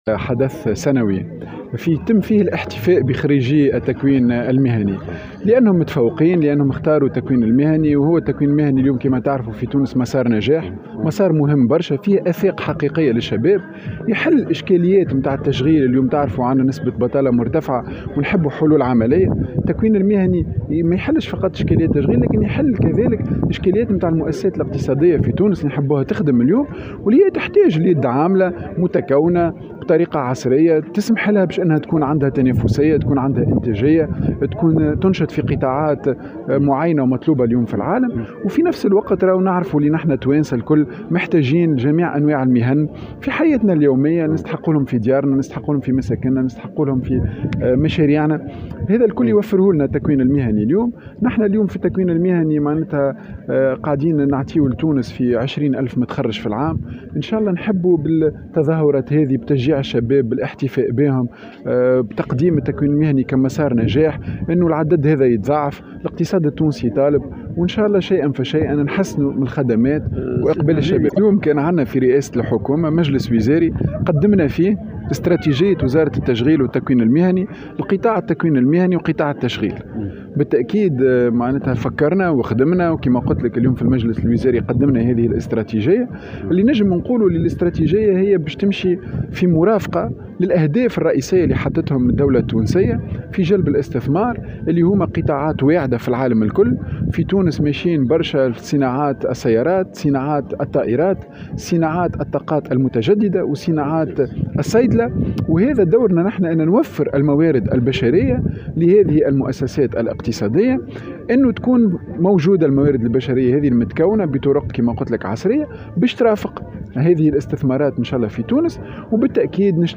وابرز، في تصريح لمراسلة الجوهرة أف أم، انه سيتم توفير الموارد البشرية المتخصصة والمتكونة بطرق عصرية لهذه المؤسسات الاقتصادية وتوفير التقنيات الحديثة والمتطورة التي ستمكن الشباب من مواكبة التطورات في هذا ا المجال وتدارك النقائص وعقد شراكات مع القطاع الخاص في هذا المجال.